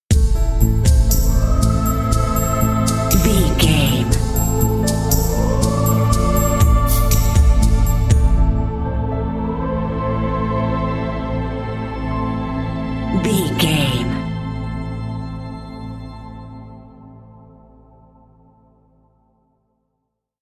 Aeolian/Minor
Slow
synthesiser
piano
percussion
drum machine
tension
ominous
dark
suspense
haunting
creepy